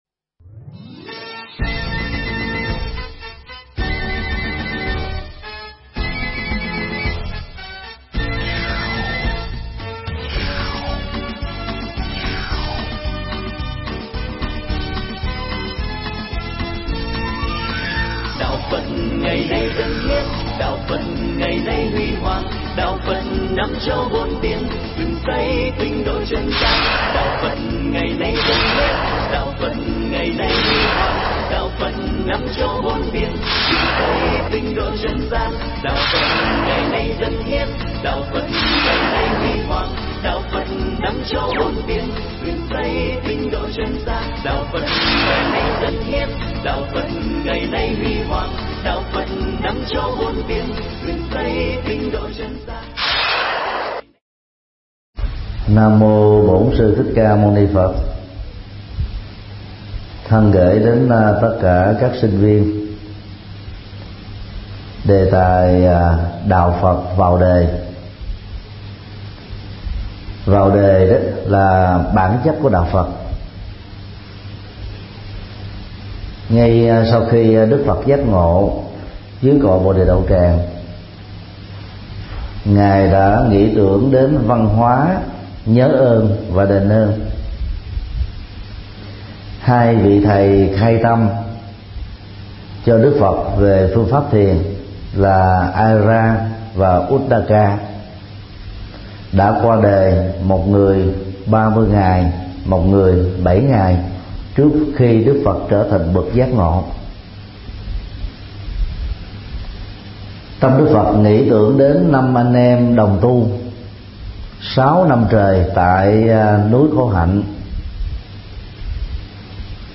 Tải mp3 Pháp thoại Đạo Phật vào đời do TT. Thích Nhật Từ giảng tại Cơ sở sinh hoạt tạm của chùa Giác Ngộ (139/5 phan đăng lưu, phường 2, quận phú nhuận, ngày 15 tháng 12 năm 2013